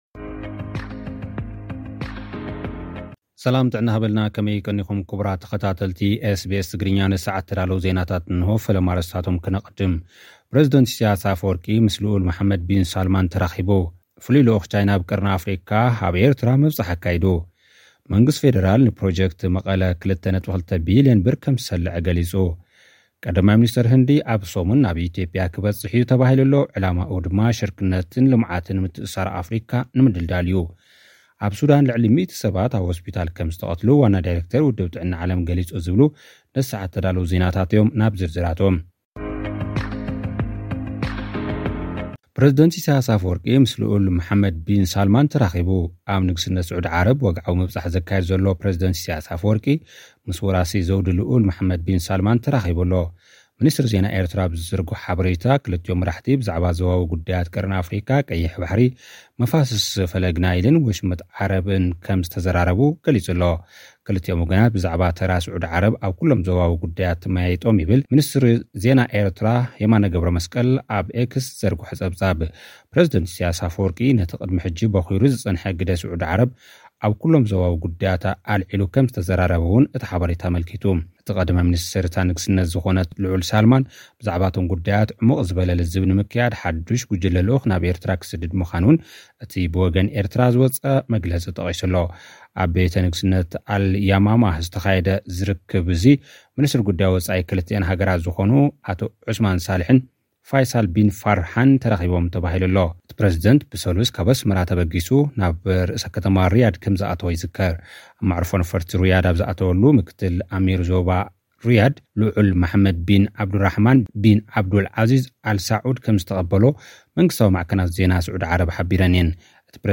መንግስቲ ፌደራል ንፕሮጀክት መቐለ 2.2 ቢልዮን ብር ሰሊዑ። (ጸብጻብ ልኡኽና)